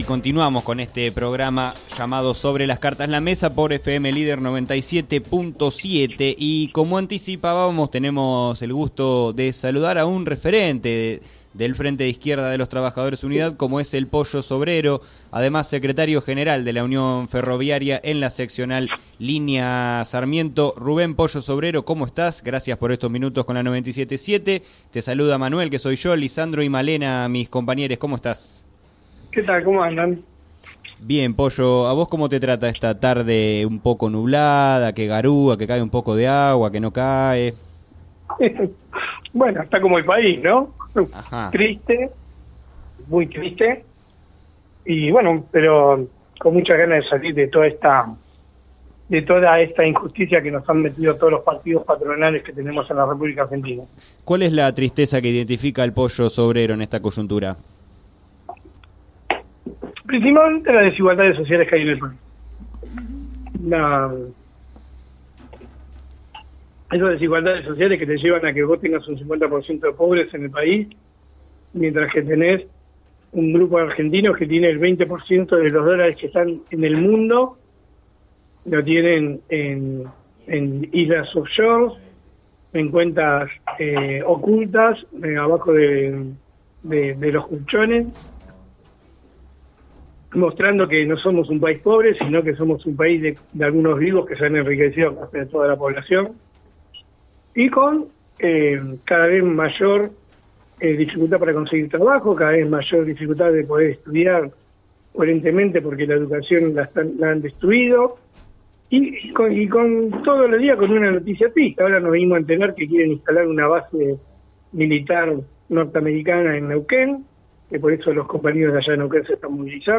Entrevistado en el programa “Sobre las cartas la mesa” de FM Líder 97.7